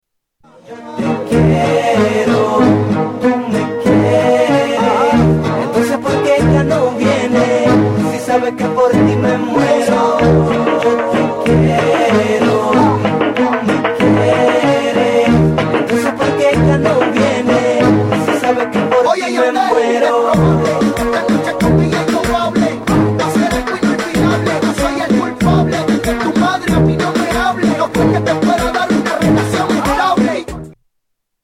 Latin Music Ringtones